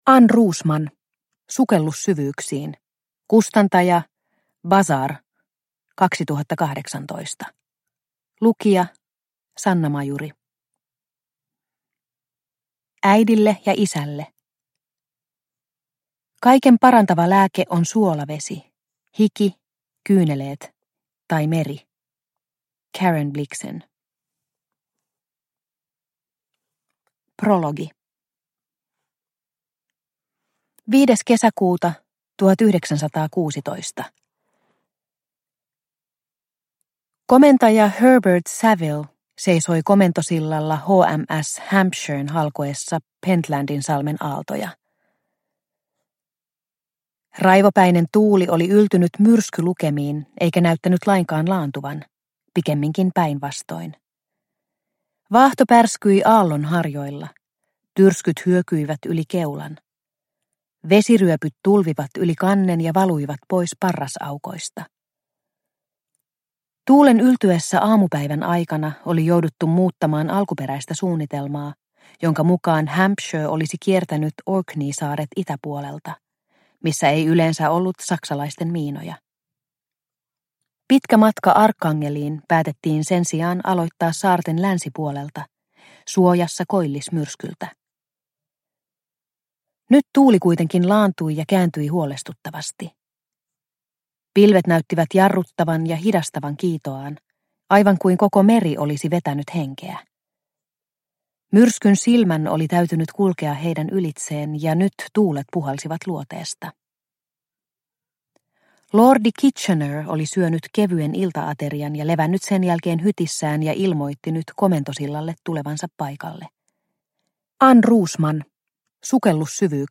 Sukellus syvyyksiin – Ljudbok – Laddas ner